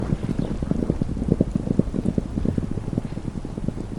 gallop.mp3